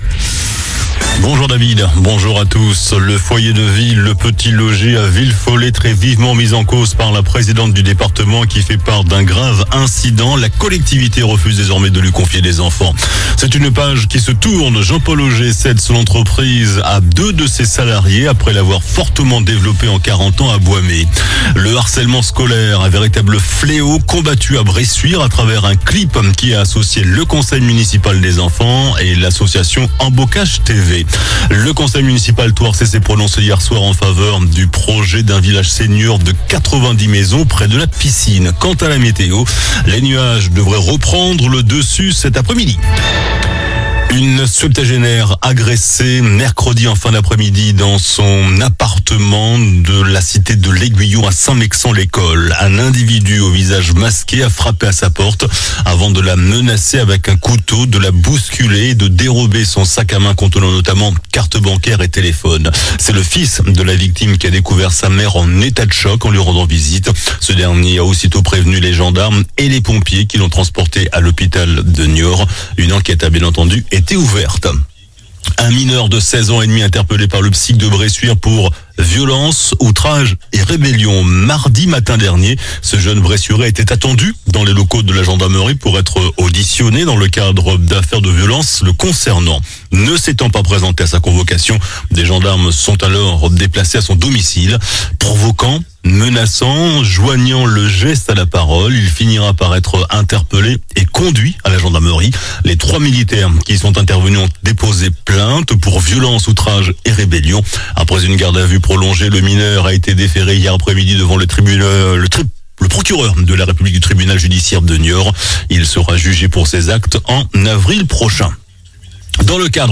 JOURNAL DU VENDREDI 31 JANVIER ( MIDI )